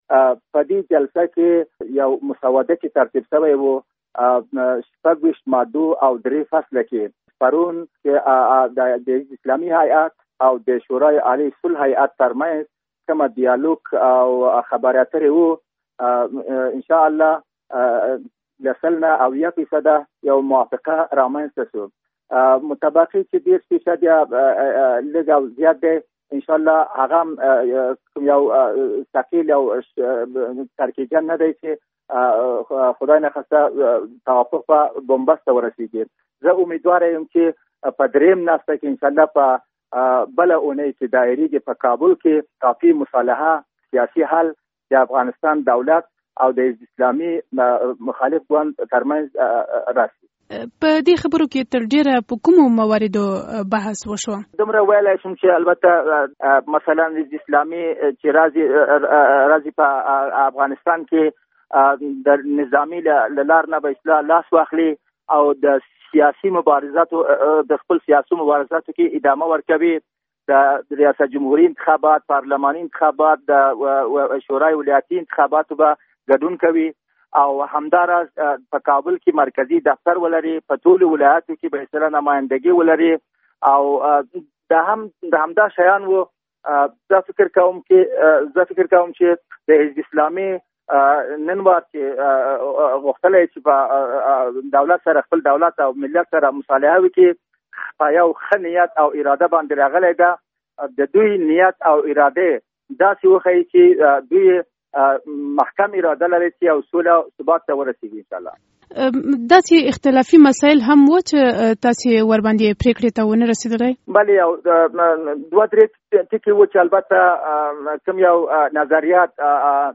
د سولې عالي شورا له مرستیال عبدالخبیر اوچقون سره مرکه